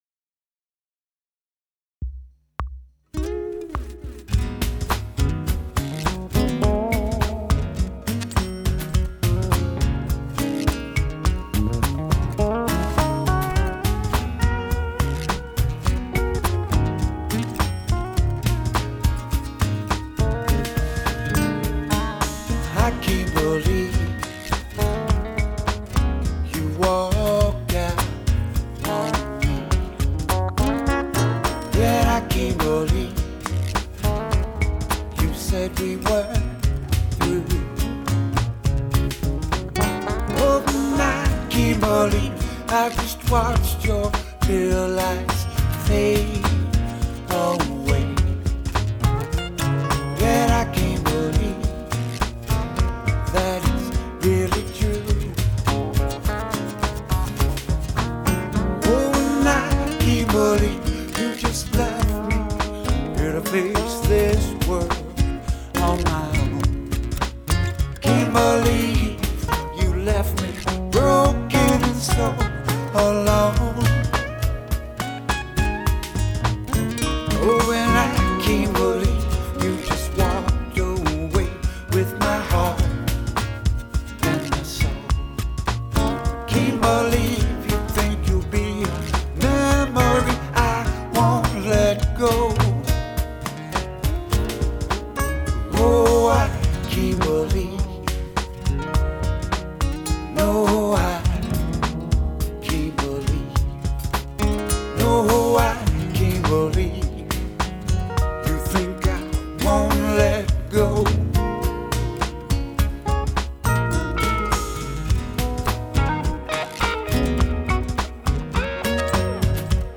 I did a lot of editing and made eq changes.